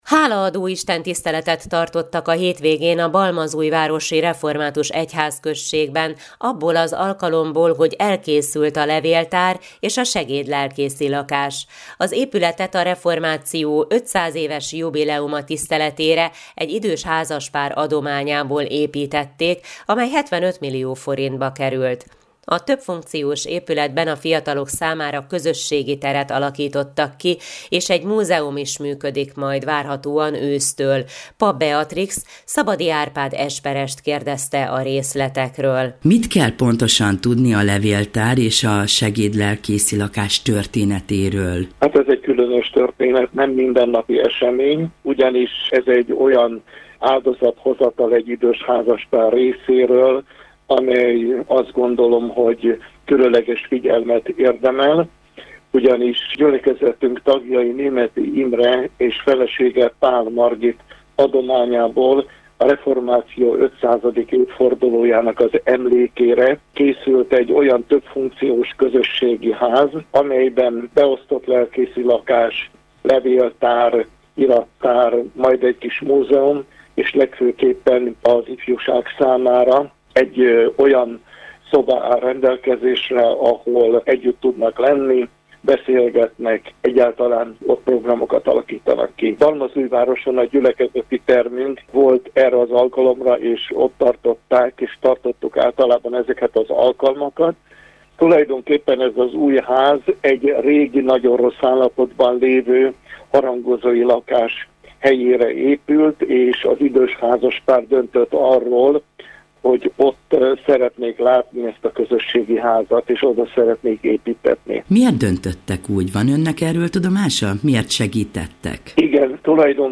János evangéliumának tizenkettedik fejezete alapján hirdette az igét Fekete Károly, a Tiszántúli Református Egyházkerület püspöke a felszentelő istentiszteleten.